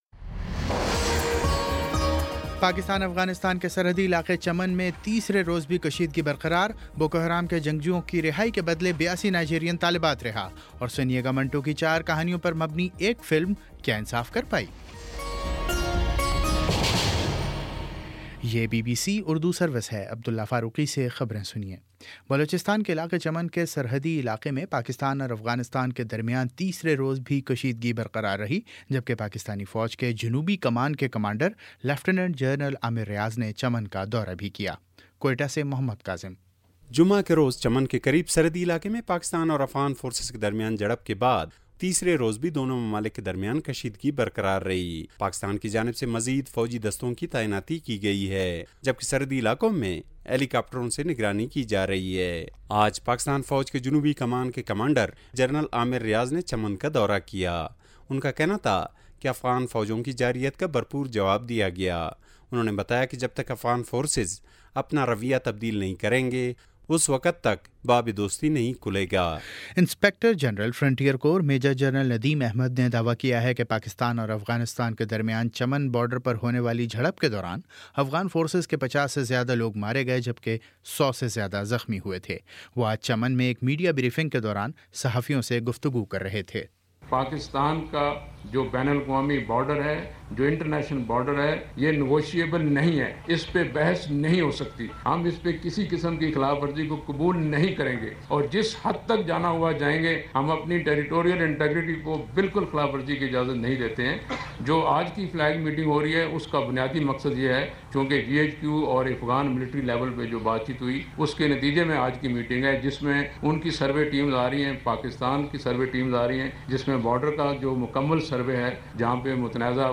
مئی 07 : شام سات بجے کا نیوز بُلیٹن